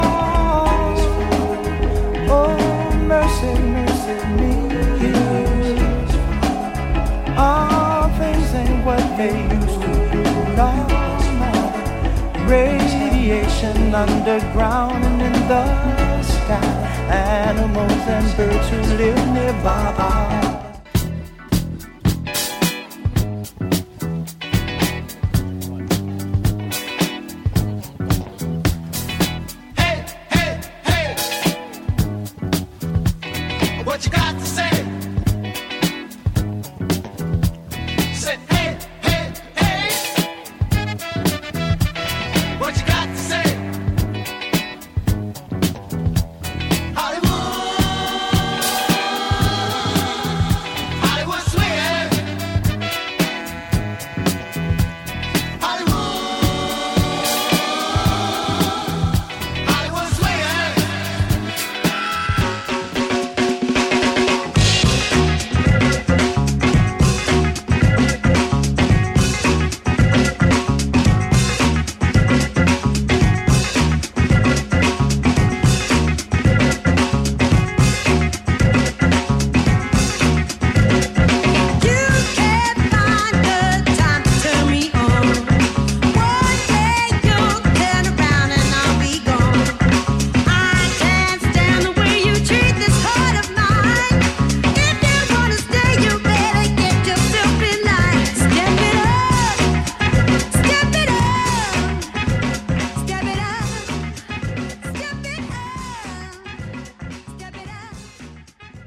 Funk and Soul Hits All Day
Mix of Funk and Soul Hits for All Day